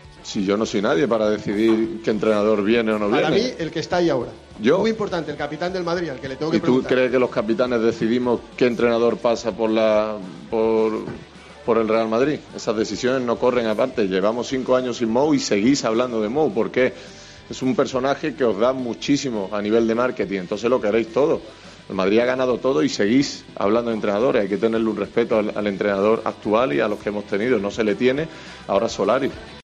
El capitán del Real Madrid habló de una posible vuelta de Mourinho en la previa de la final del Mundialito.